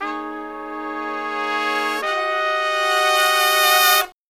Index of /90_sSampleCDs/USB Soundscan vol.29 - Killer Brass Riffs [AKAI] 1CD/Partition A/02-91DOIT S1